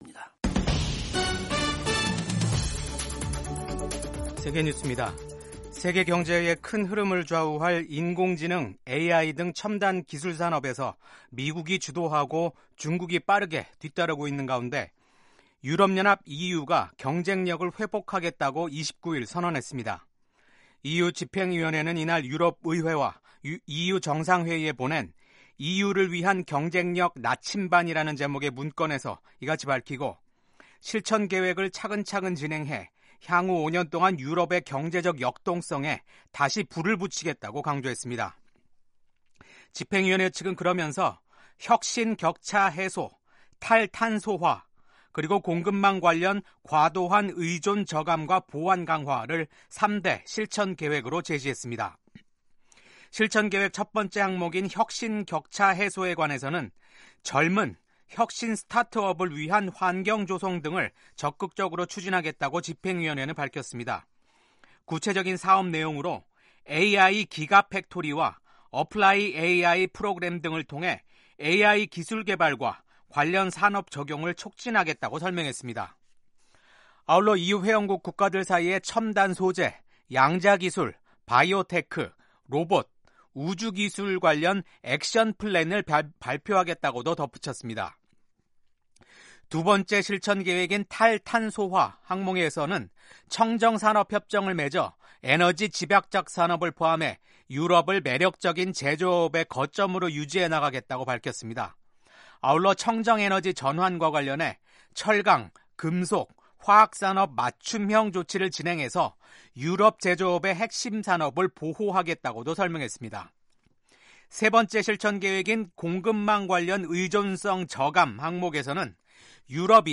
세계 뉴스와 함께 미국의 모든 것을 소개하는 '생방송 여기는 워싱턴입니다', 2025년 1월 30일 아침 방송입니다. 도널드 트럼프 미국 행정부가 거의 모든 대외 원조를 일시 중단한 가운데 국제 사회에서 우려가 제기되고 있습니다. 미국 연방 판사가 트럼프 정부의 연방 보조금과 대출금 동결 집행을 일시 차단했습니다. 미국 정부는 또 2월 6일까지 퇴직 의사를 밝히는 연방 공무원들에게 최대 8개월 치 급여 보장을 제안했습니다.